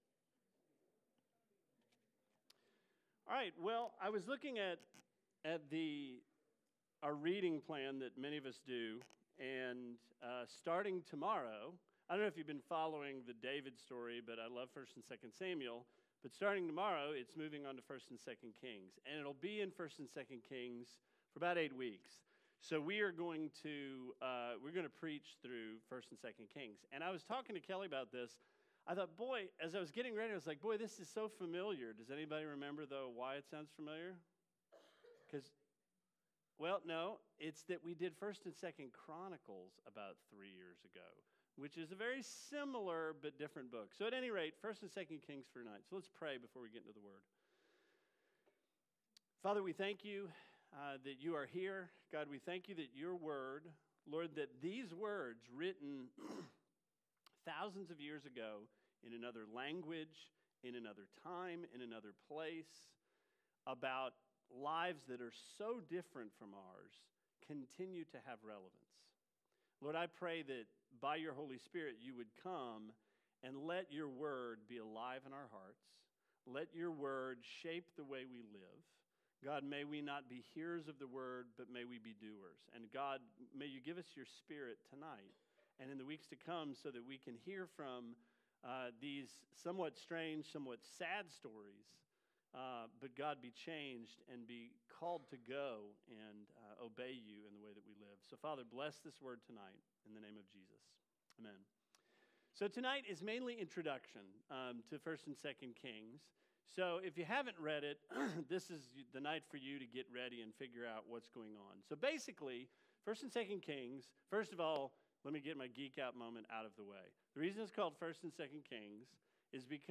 Sermon 07/13: Intro Into 1&2 Kings